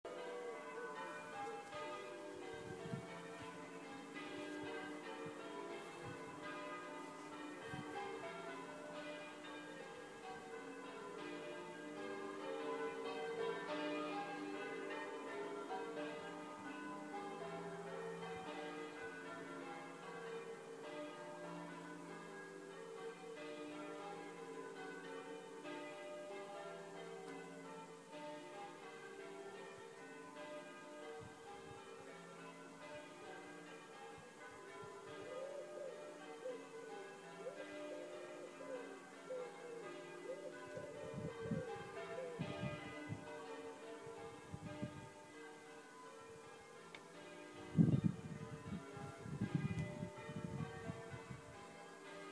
Next Train guard on London Underground this morning. It gets clearer from about 30 seconds in...